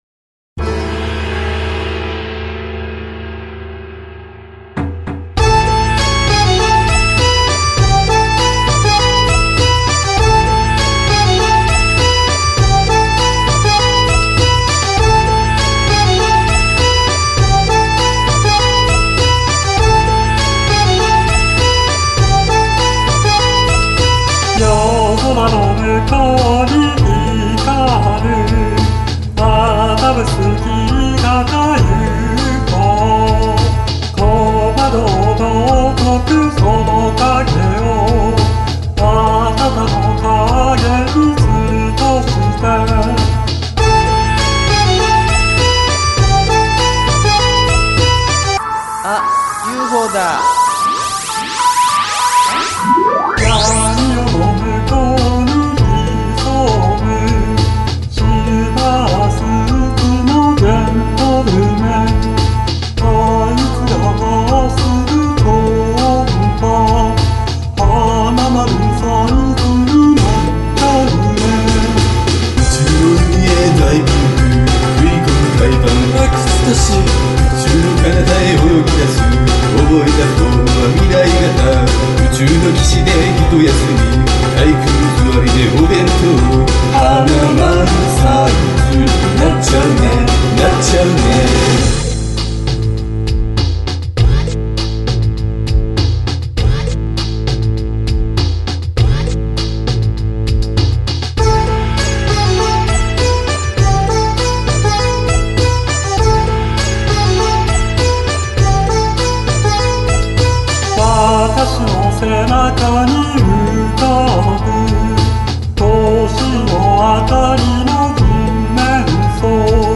中国臭が漂う